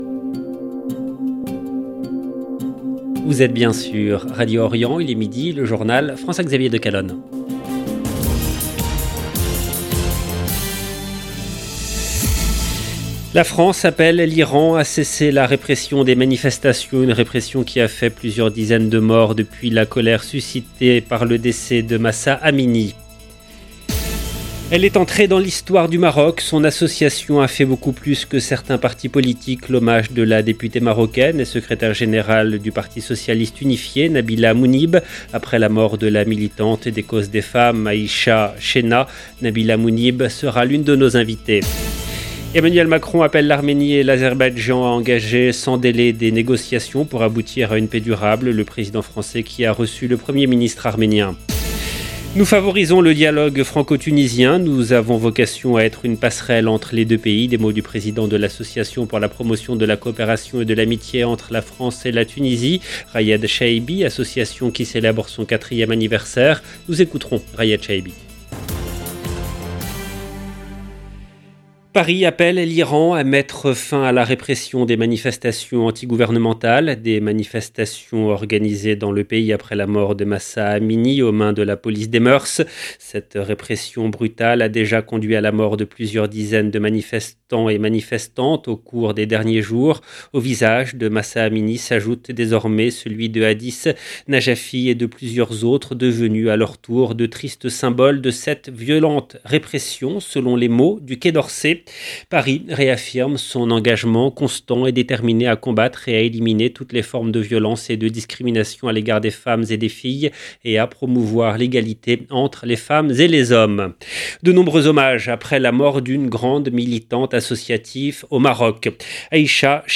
Nabila Mounib sera l’une de nos invitées.